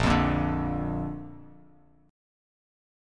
Bb Chord
Listen to Bb strummed